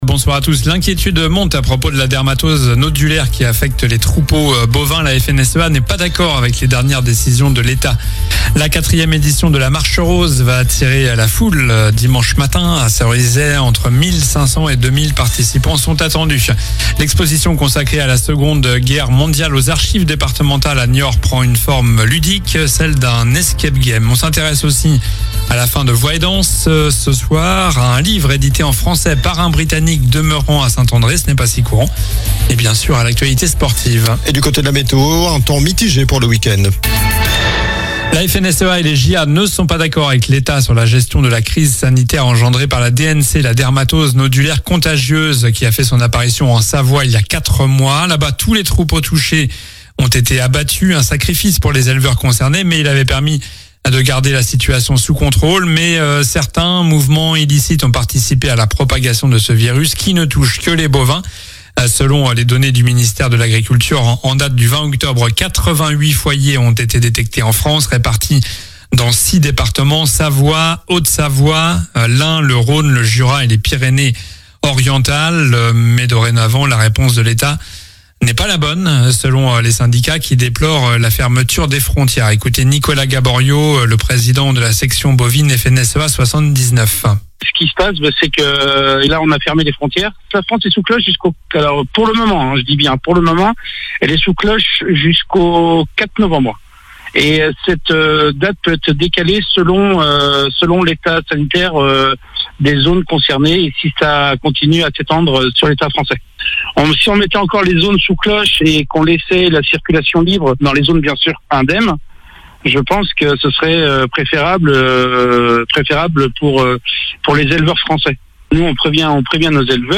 Journal du vendredi 24 octobre (soir)